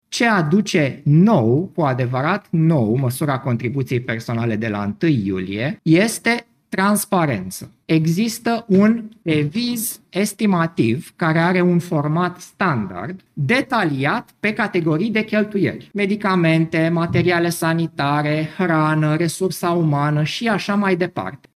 O persoană asigurată se poate trata la privat, dacă privatul este în contract cu casele de asigurări de sănătate, a explicat șeful Casei Naționale a Asigurărilor de Sănătate, Adrian Gheorghe: “Ce aduce cu adevărat nou măsura contribuției personale de la 1 iulie este transparența. Există un deviz estimativ care are un format standard, detaliat pe categorii de cheltuieli – medicamene, materiale sanitare, hrană, resursa umană.”